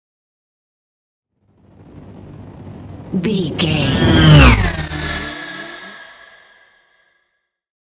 Sci fi ship vehicle pass by
Sound Effects
futuristic
pass by